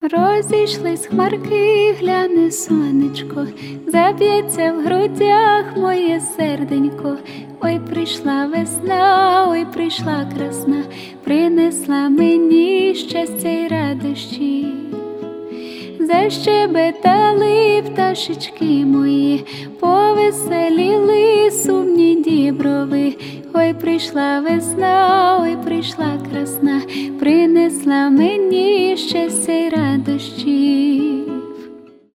поп , зарубежные , фолк
нежные
красивый вокал